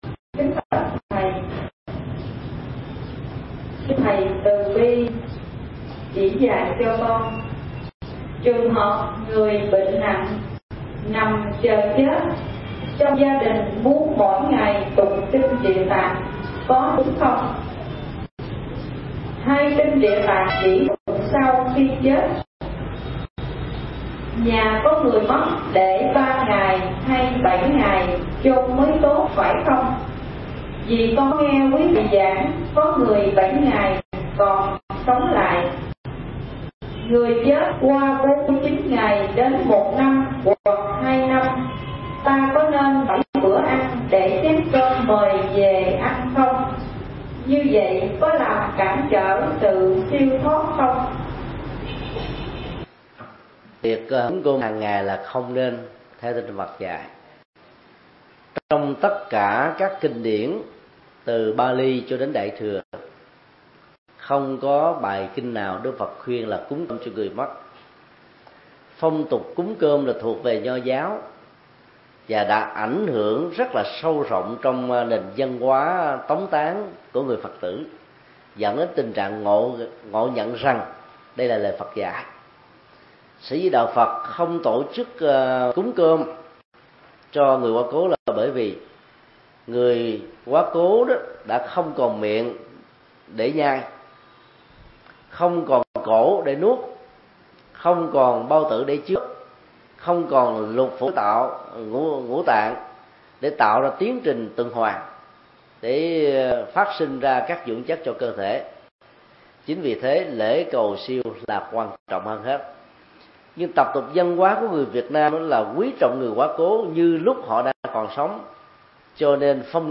Vấn đáp: Lễ cầu siêu theo tinh thần Phật giáo – Thầy Thích Nhật Từ mp3